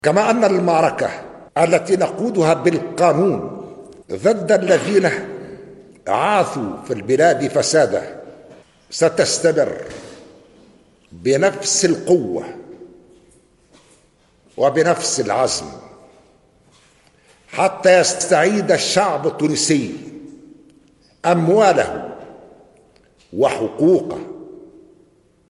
تصريح-رئيس-الجمهورية.mp3